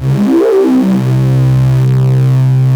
OSCAR 10 C2.wav